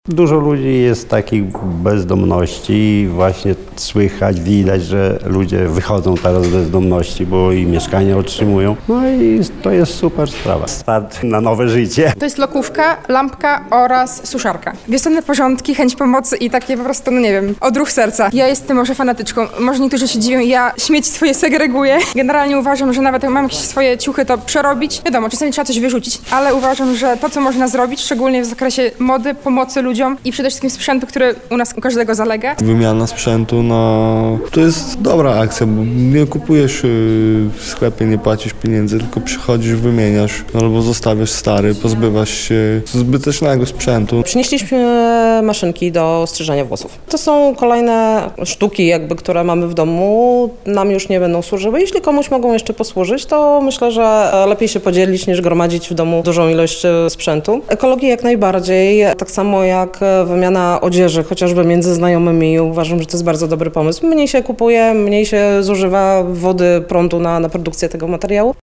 Z osobami uczestniczącymi w wydarzeniu „Wiosenna wymiana małego sprzętu RTV i AGD” rozmawiała
27-sonda-sprzet-rtv-agd.mp3